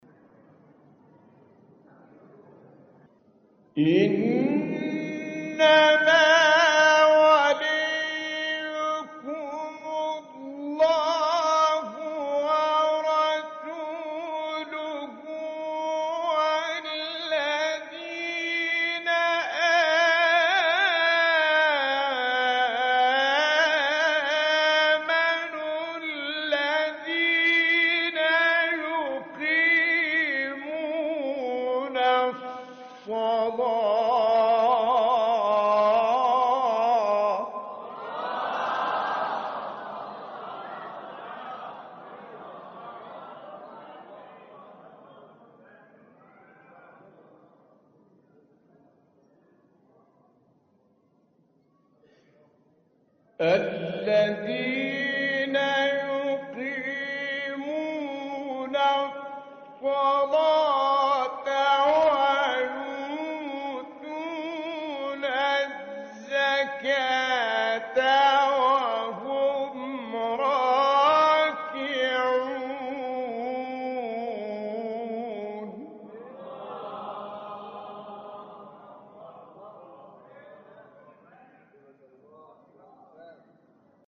تلاوت آیه ولایت با صوت سید متولی عبدالعال
برچسب ها: مقاطع صوتی از تلاوت ، آیه ولایت ، فرازی از تلاوت آیه ولایت ، تلاوت آیه ولایت از قاری مصری ، تلاوت آیه ولایت از قاری ایرانی